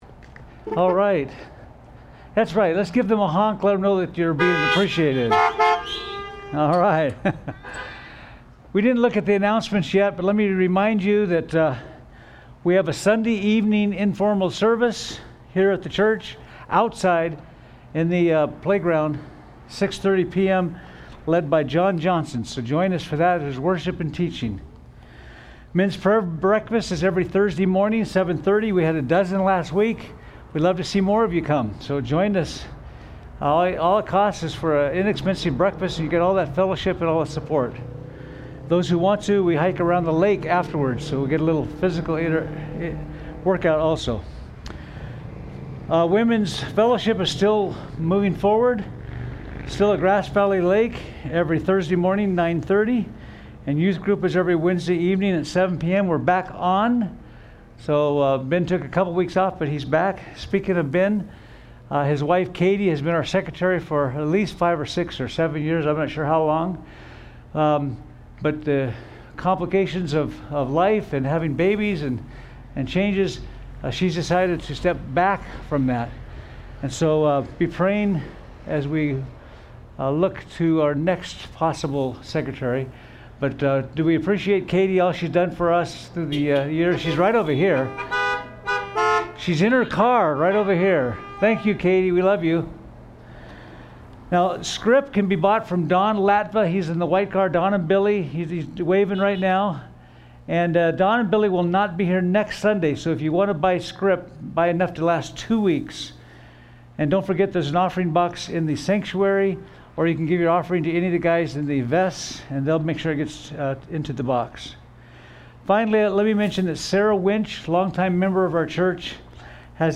Sermons
Drive-In Service